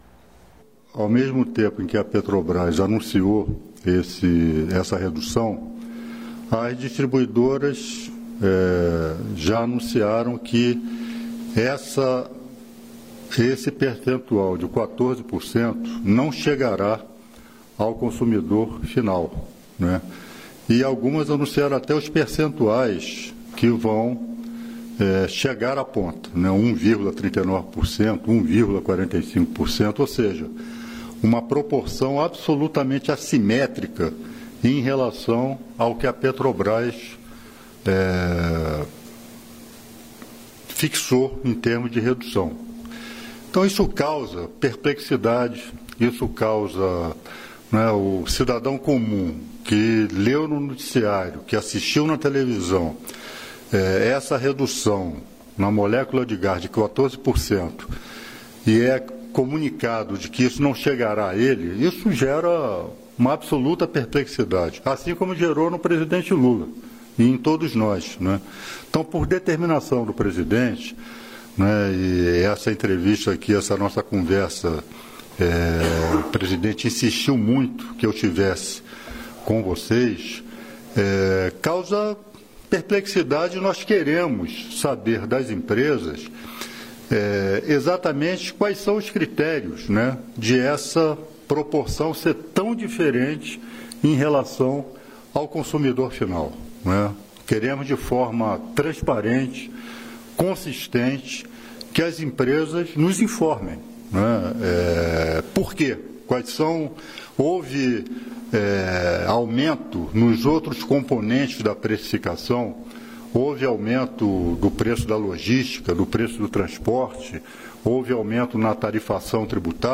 Íntegra da coletiva de imprensa concedida pelo secretário nacional do Consumidor, Wadih Damous, sobre os preços abusivos do gás, nesta quarta-feira (30), em Brasília.